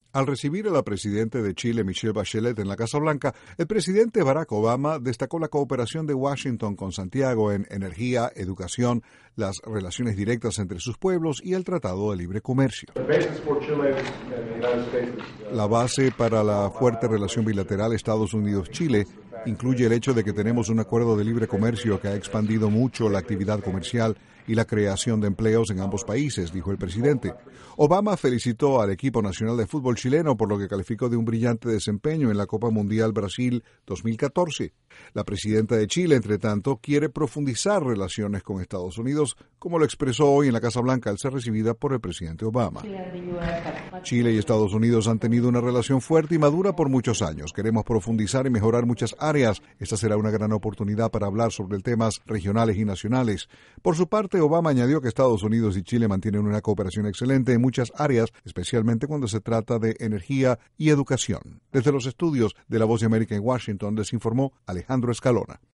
INTRO La mandataria chilena Michele Bachelet fue recibida en La Casa Blanca y sostuvo una serie de reuniones con el presidente Barack Obama y el Vice Presidente Joe Biden. Desde la Voz de América en Washington informa